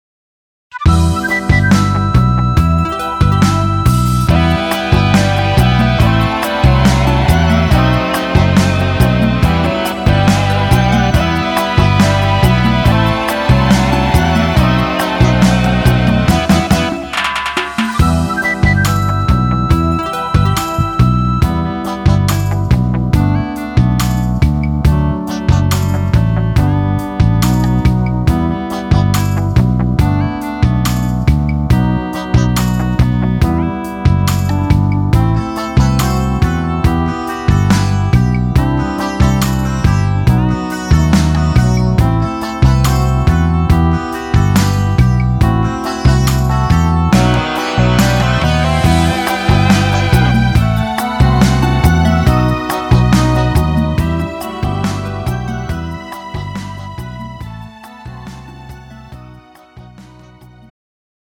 음정 남자키 3:23
장르 가요 구분 Pro MR